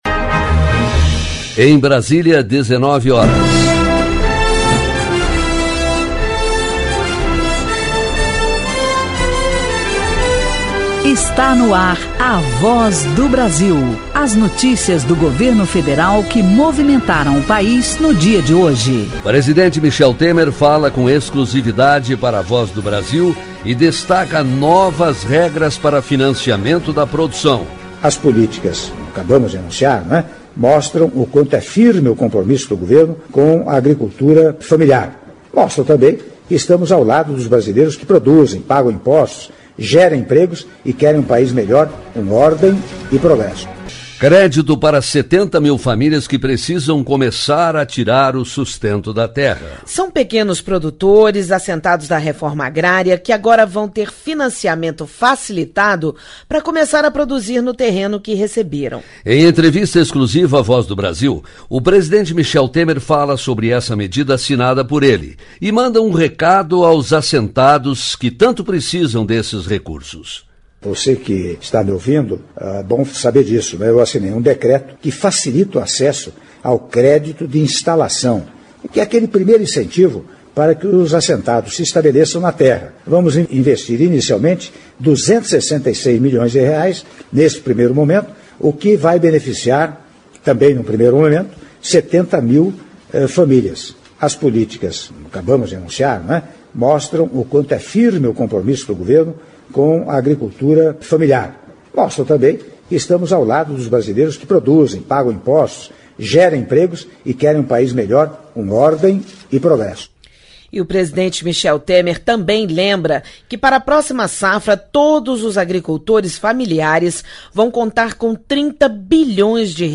Áudio da entrevista exclusiva concedida pelo Presidente da República, Michel Temer - para Voz do Brasil (03min15s) - Brasília/DF — Biblioteca